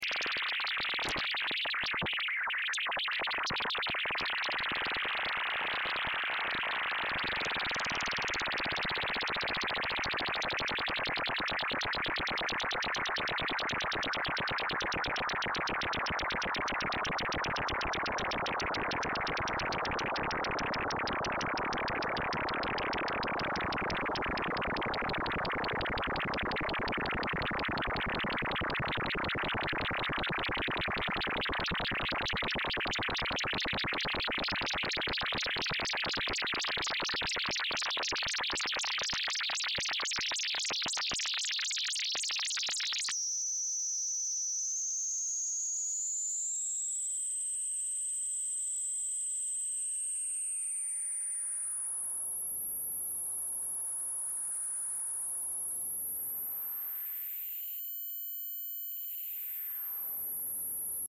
............. Il 14 gennaio 2005 l'orbiter Cassini ha udito suoni dalla sonda Huygens mentre questa scendeva su Titano
file sonoro, che dura un minuto, è la contrazione di 4 ore di segnali audio che vanno da quando la sonda ha aperto il suo paracadute principale, all'impatto sul terreno, a circa un ora passata sulla supericie del satellite di Giove. Questi non sono suoni di altri mondi, sono i segnali che poermettono di ricavare notizie sulla posizione della sonda.